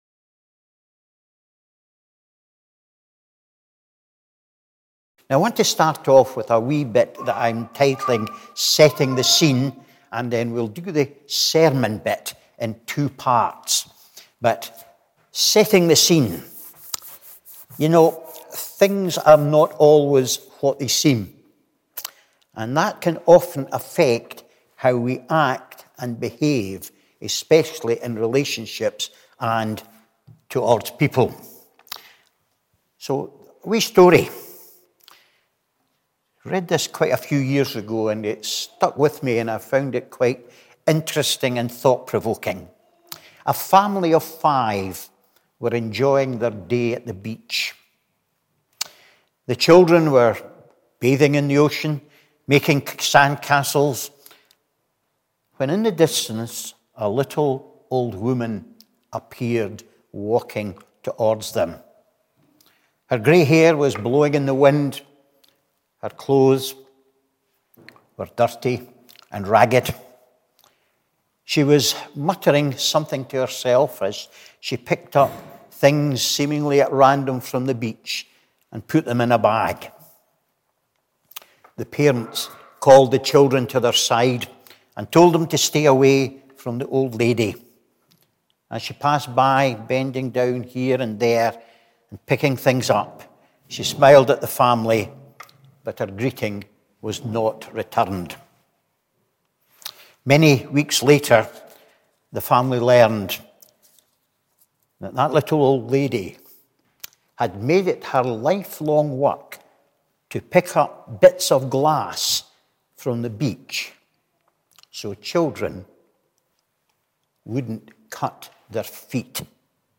Morning Service 12th September 2021 – Cowdenbeath Baptist Church
Morning Service 12th September 2021